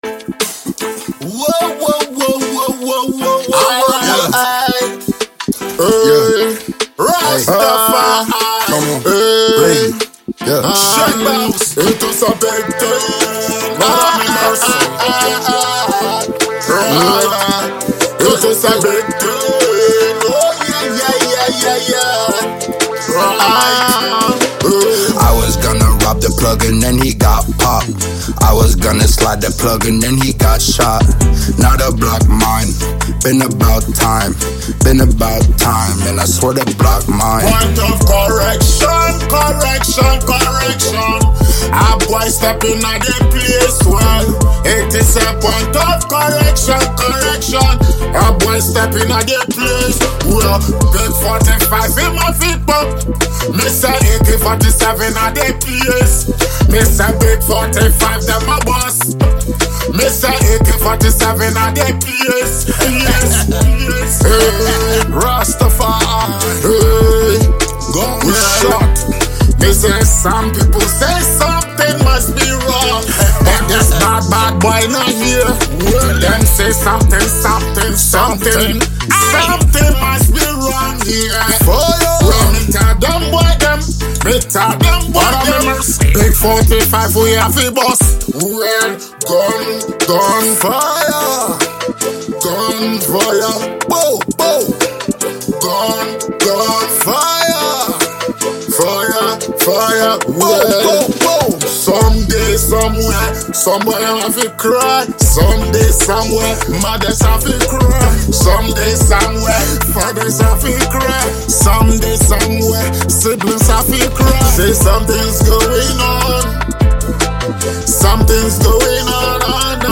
vocals with an instrumental that keeps you nodding